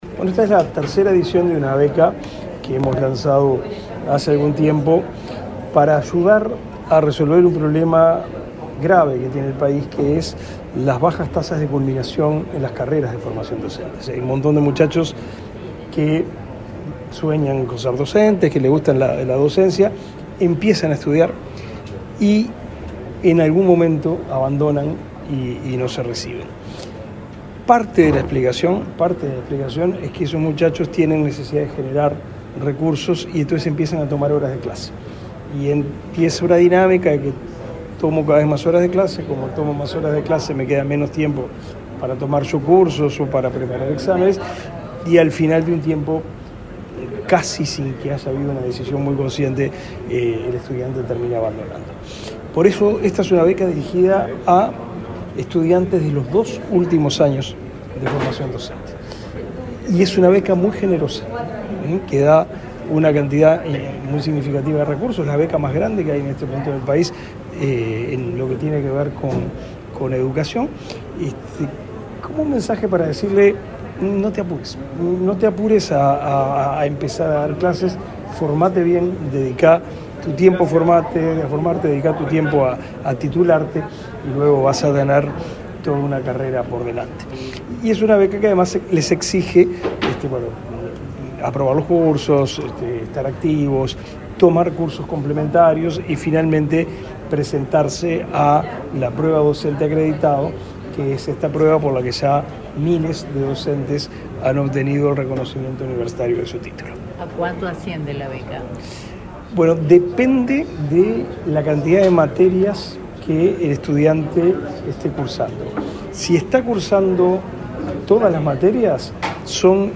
Declaraciones del ministro de Educación y Cultura, Pablo da Silveira
El ministro de Educación y Cultura, Pablo da Silveira, dialogó con la prensa, luego de participar en el lanzamiento del programa Beca Docente